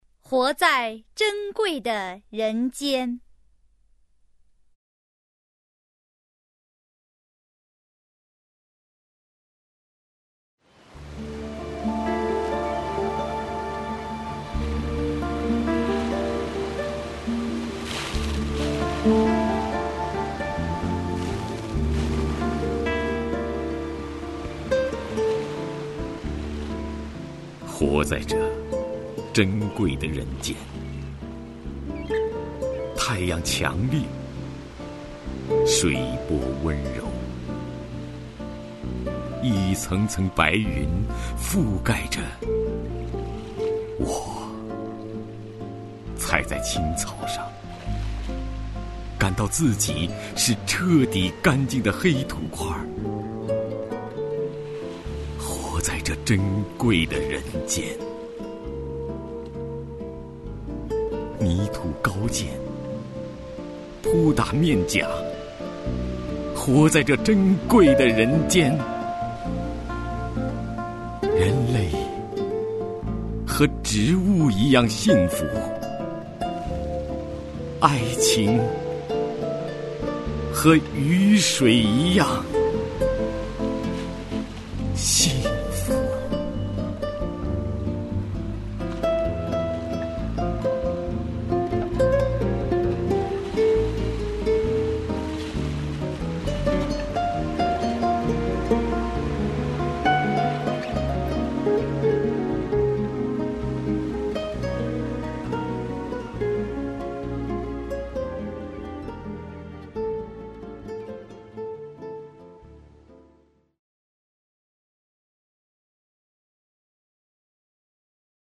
首页 视听 名家朗诵欣赏 徐涛
徐涛朗诵：《活在珍贵的人间》(海子)